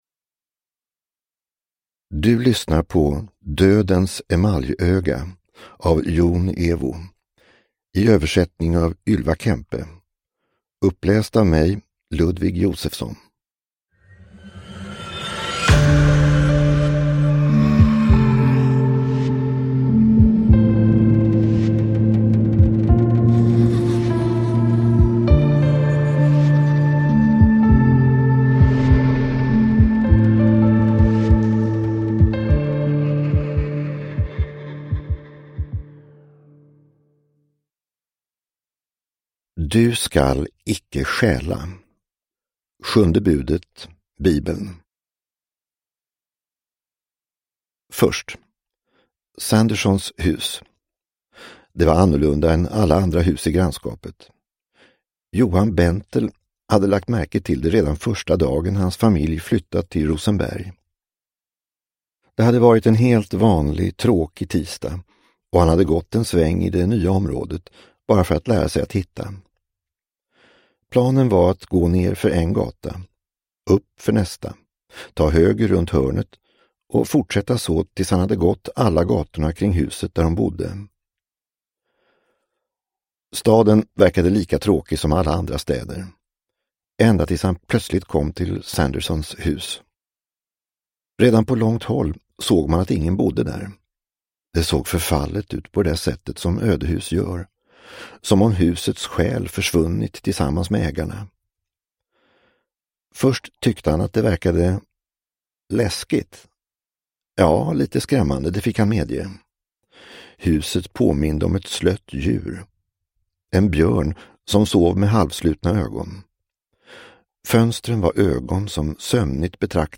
Dödens emaljöga – Ljudbok – Laddas ner